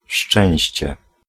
Ääntäminen
US : IPA : /ˈfɔɹ.tʃən/ UK : IPA : /ˈfɔː.tʃuːn/